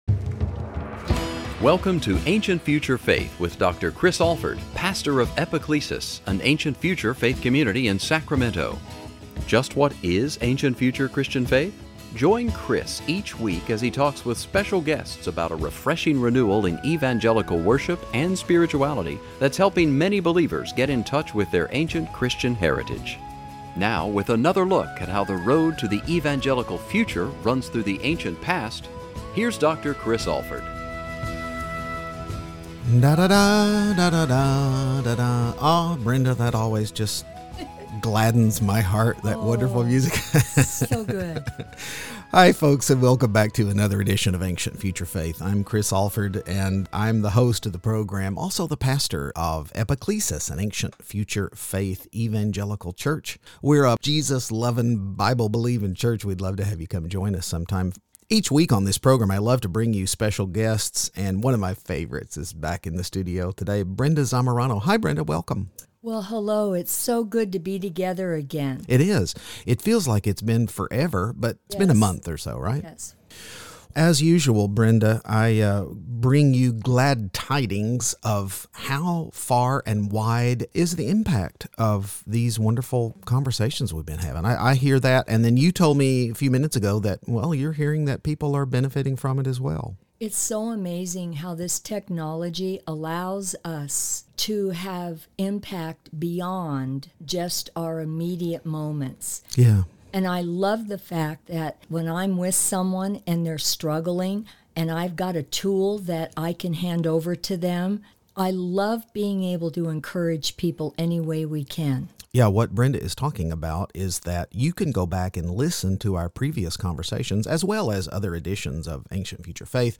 Join us for a wonderful conversation about shame and pain and, surprisingly, pride.